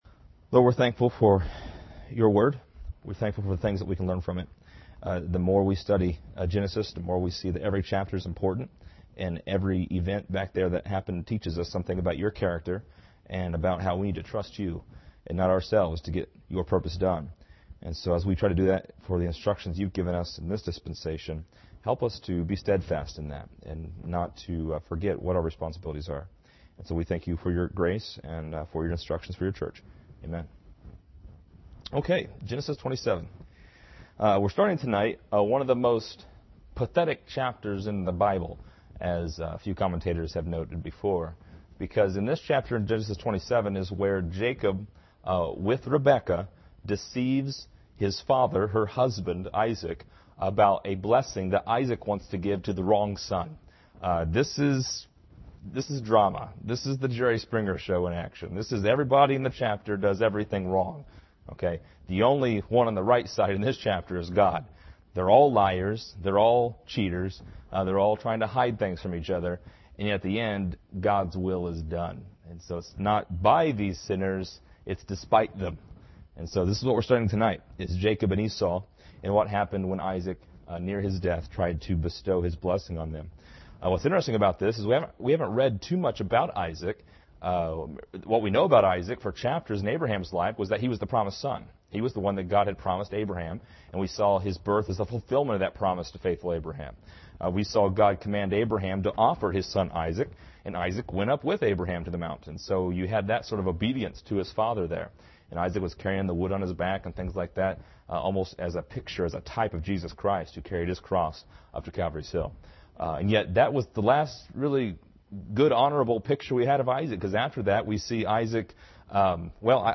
This lesson is part 36 in a verse by verse study through Genesis titled: Jacob and Esau.